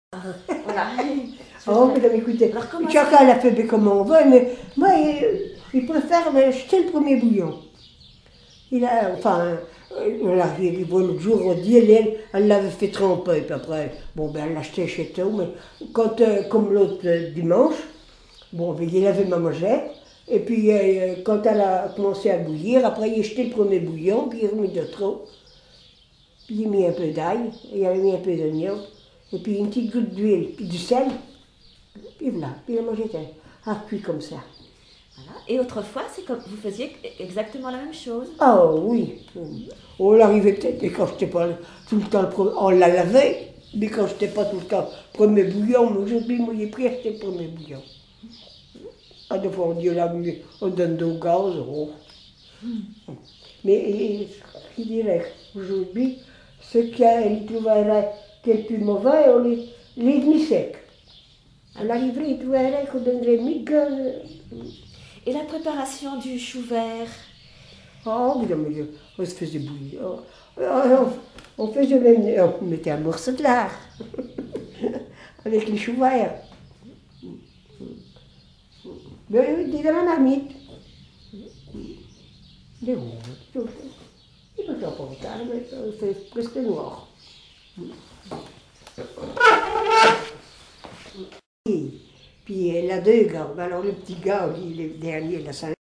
Témoignage sur la vie de l'interviewé(e)